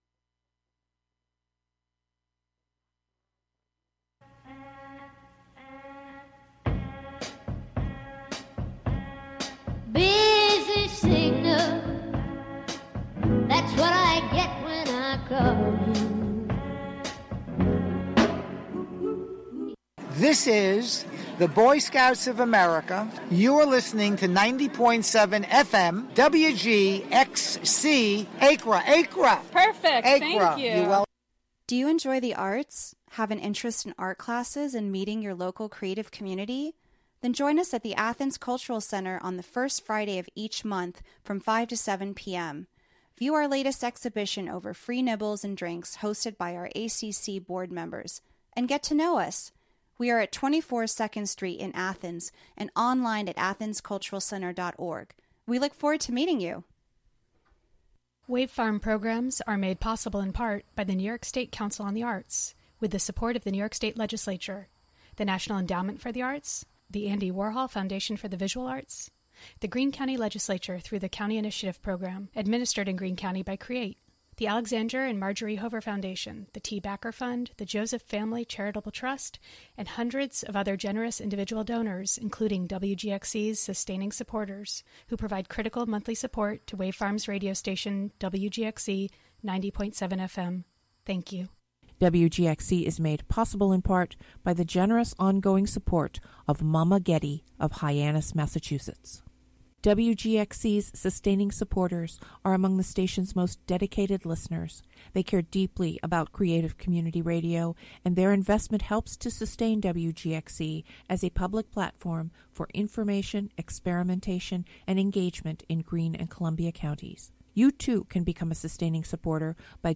Call in to request your favorite songs and share how you feel about the hot topics of the week. Live from the WGXC Hudson Studio.